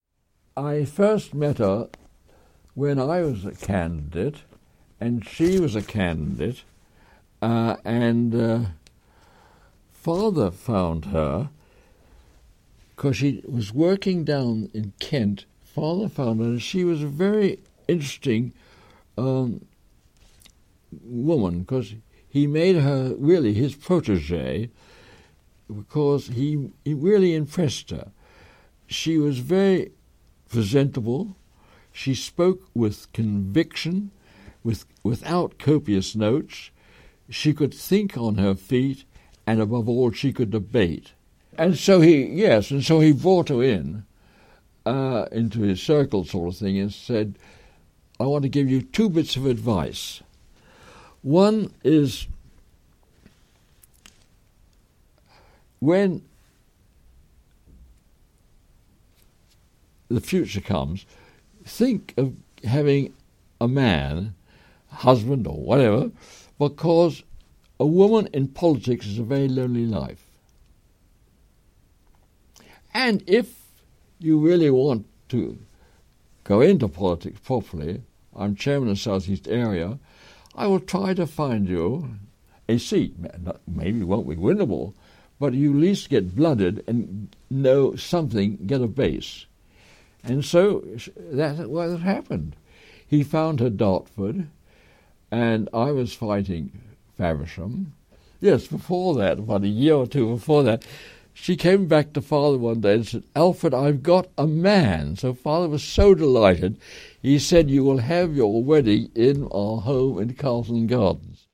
Here we take a look back on our interview with him…
Here Bossom describes his father’s role in encouraging the young Margaret Roberts, as she was when they first met, into politics: